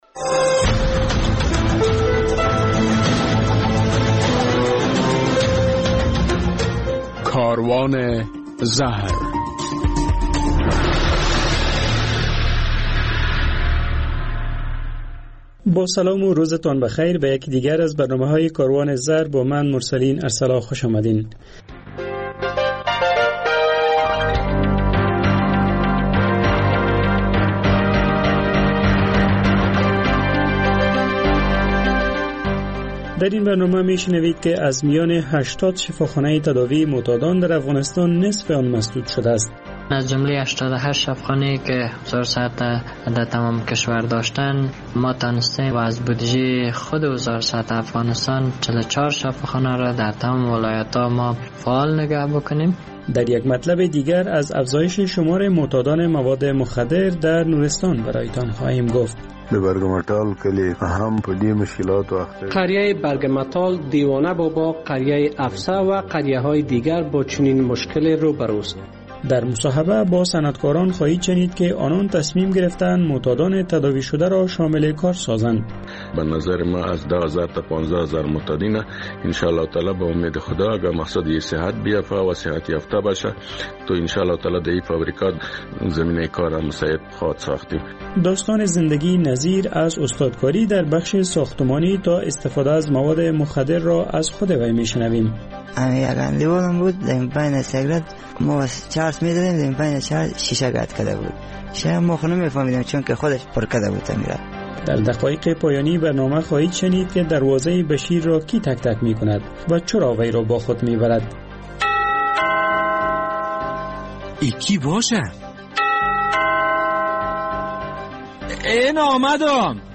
در برنامه این هفته کاروان زهر این مطالب گنجانیده شده اند: از میان ۸۸ مرکز ترک اعتیاد در افغانستان نصف آن مسدود شده است گزارش در مورد افزایش شمار معتادان در ولایت نورستان مصاحبه در مورد این که فابریکه داران در پارک های صنعتی کابل برای معتادان تداوی شده زمینه کار را فراهم می‌سازند خاطره یک فرد...